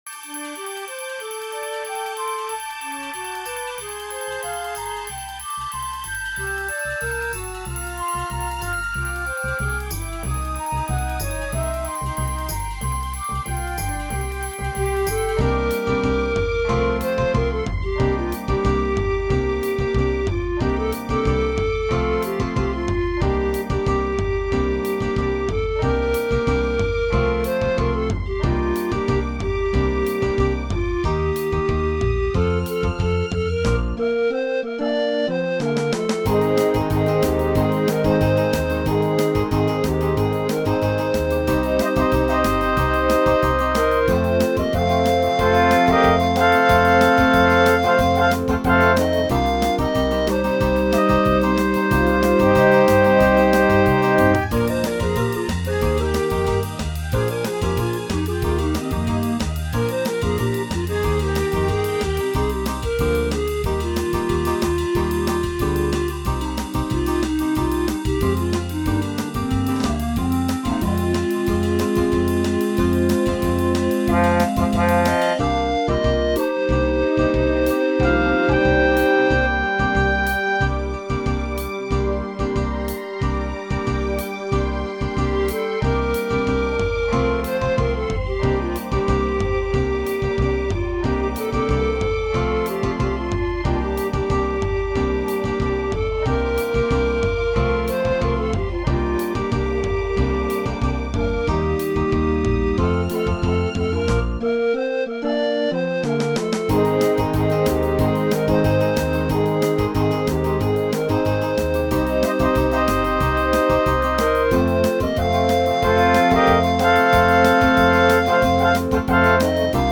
Comédie musicale rock
Remix instrumental